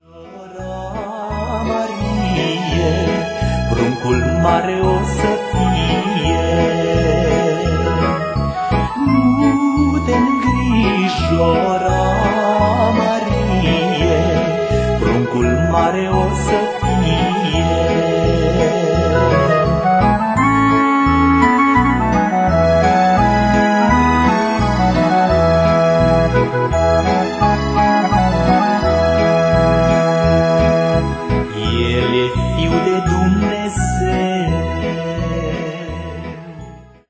colinde